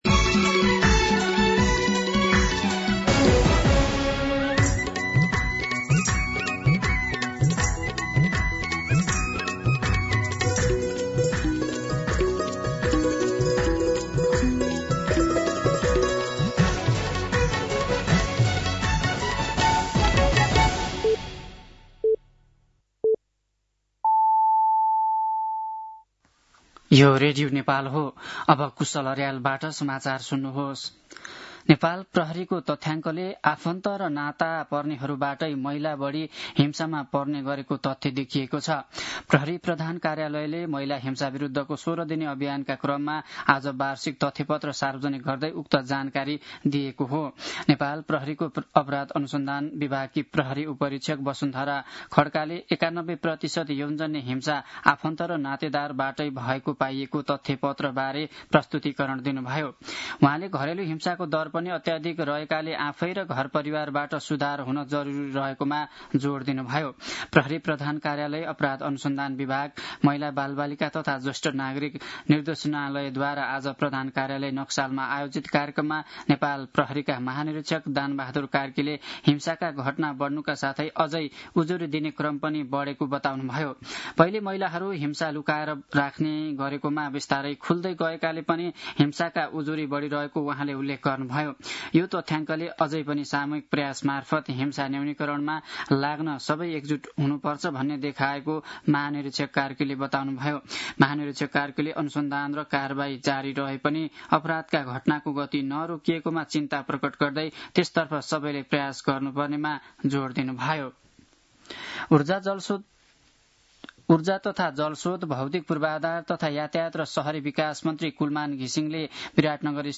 दिउँसो ४ बजेको नेपाली समाचार : १४ मंसिर , २०८२